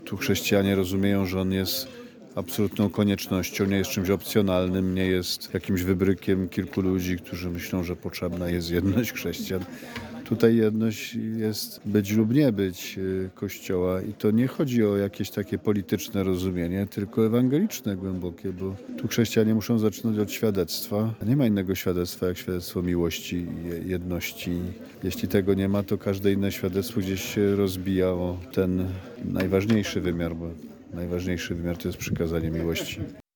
Natomiast kard. Grzegorz Ryś zwraca uwagę, że ekumenizm w Estonii jest daleko bardziej zaawansowany niż w krajach, gdzie chrześcijanie stanowią większość.